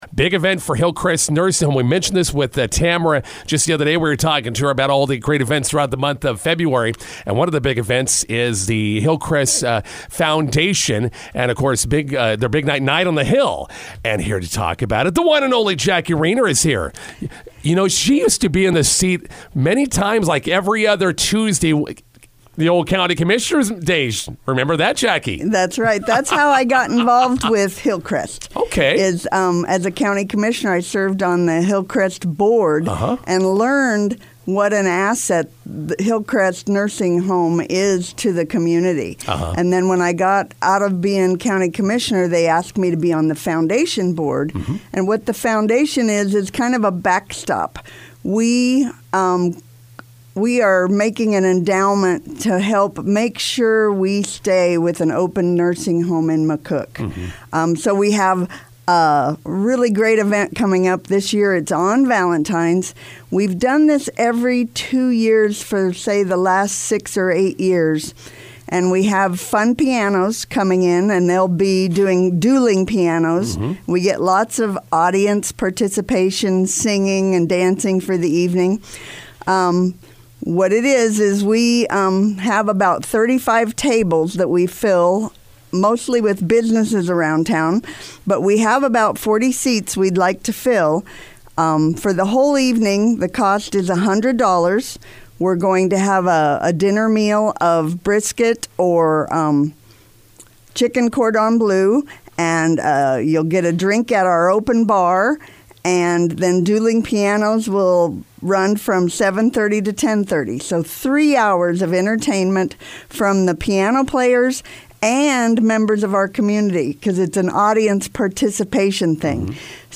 INTERVIEW: Hillcrest Nursing Foundation "Night on the Hill" fundraiser coming up on February 14th.